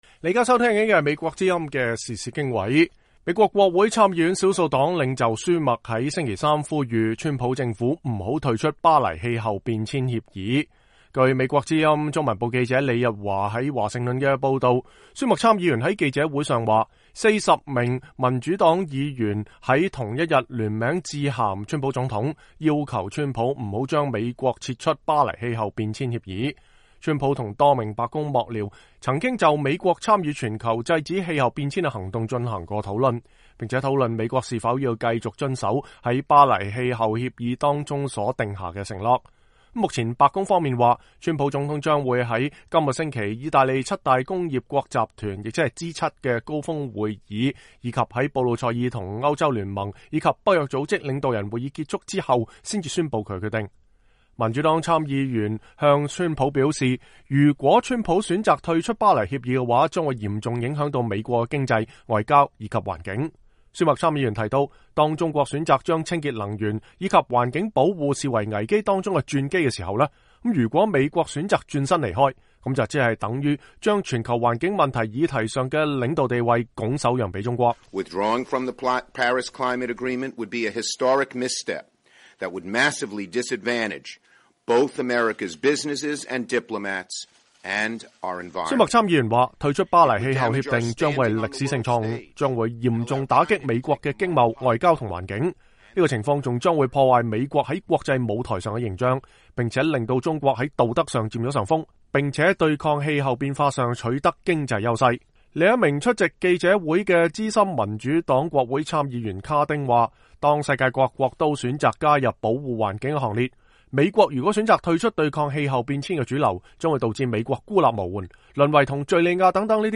舒默在記者會上說，40位民主黨參議員在同一天聯名致信總統川普，要求川普不要將美國撤出《巴黎氣候變遷協議》。
另一位出席記者會的資深民主黨參議員卡丁稱，當世界各國都選擇加入保護環境的行列，美國若選擇退出對抗氣候變遷的主流，將導致美國孤立無援，淪為和敘利亞等沒有參加的國家是同一類的。